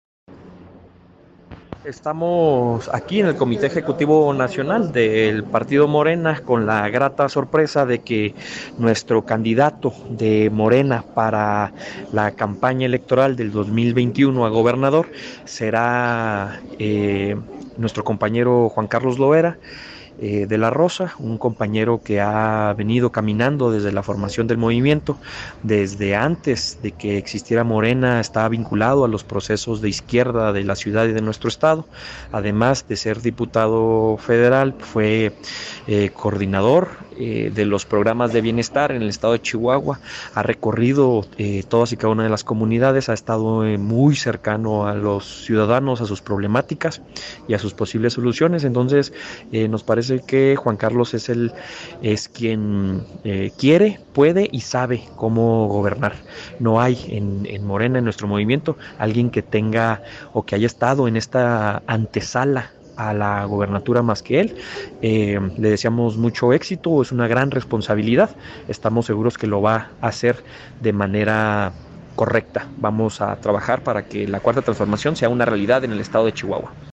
Diputado Federal, Ulises García Soto